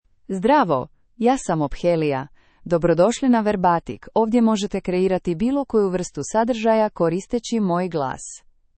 OpheliaFemale Croatian AI voice
Ophelia is a female AI voice for Croatian (Croatia).
Voice: OpheliaGender: FemaleLanguage: Croatian (Croatia)ID: ophelia-hr-hr
Voice sample
Listen to Ophelia's female Croatian voice.